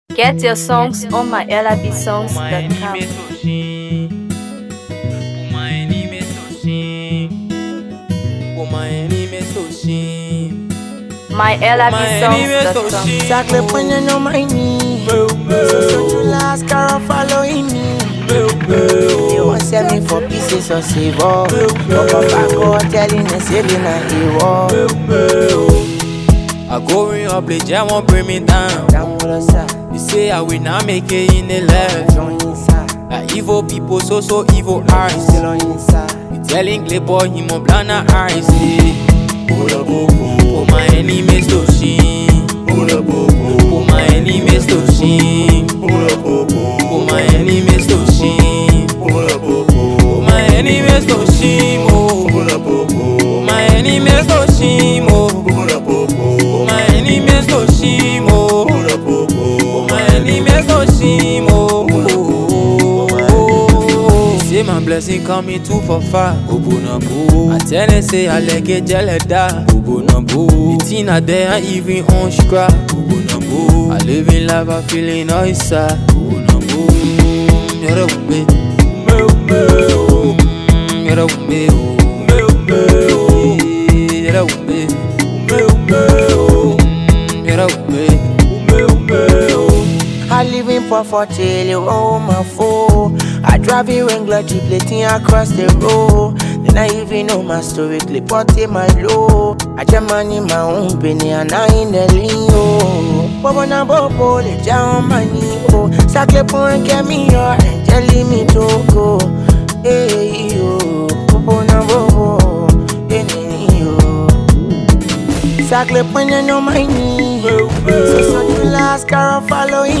Afro PopMusic
soulful Afrobeat rhythms with hard-hitting lyrical energy